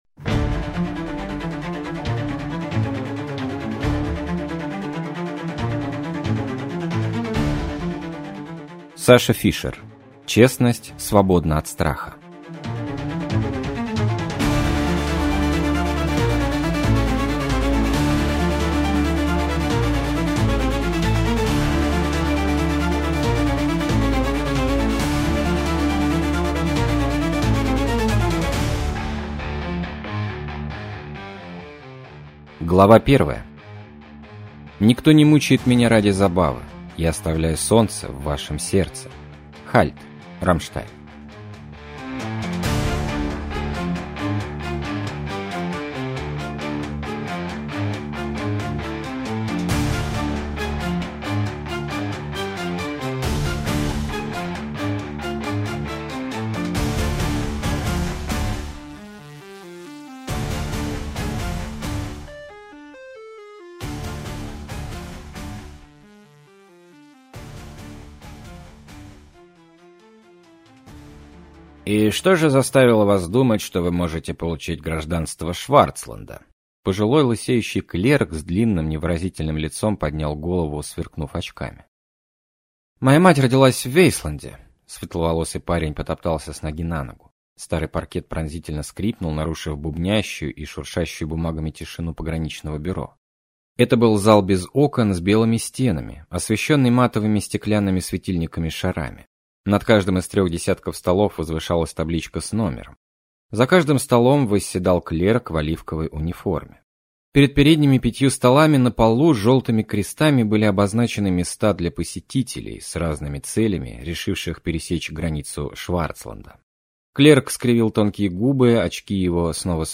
Слушать аудиокнигу Путешествия Гулливера полностью, читает: Максим Суханов